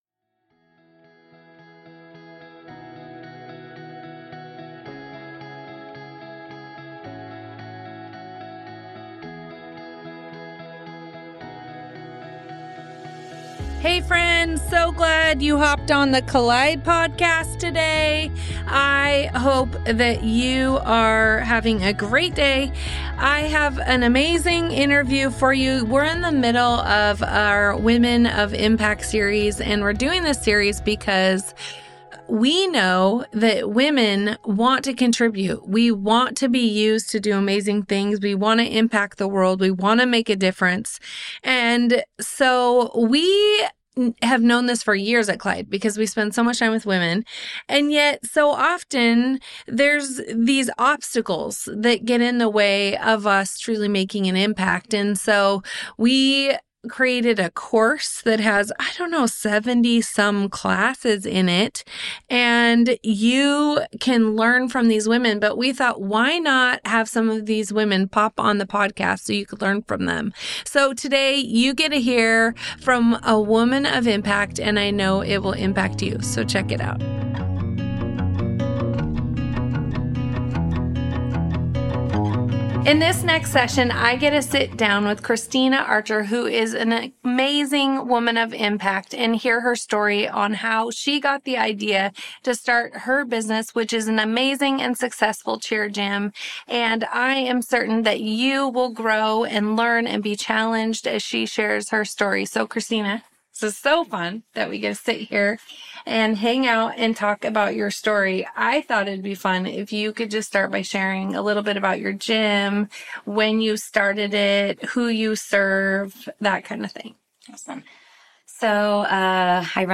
Putting Your Dreams on Paper: Women of Impact Interview